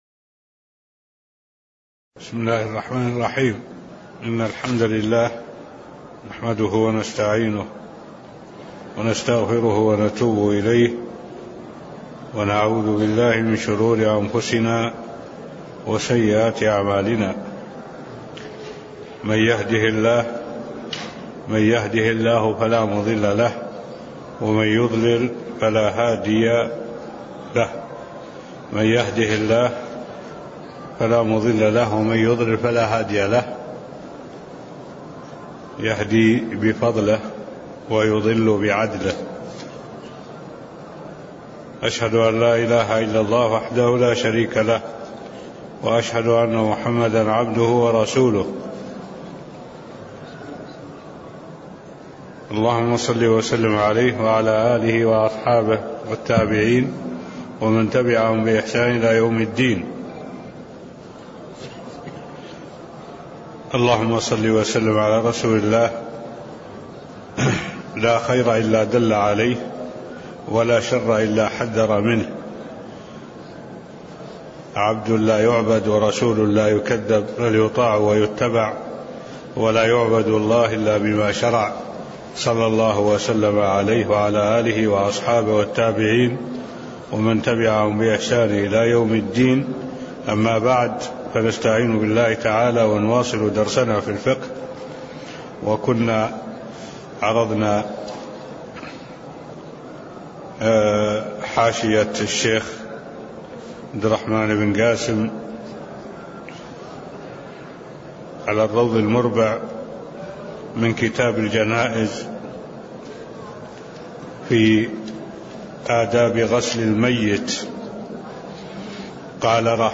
تاريخ النشر ٢٥ محرم ١٤٢٩ هـ المكان: المسجد النبوي الشيخ: معالي الشيخ الدكتور صالح بن عبد الله العبود معالي الشيخ الدكتور صالح بن عبد الله العبود آداب غسل الميت (010)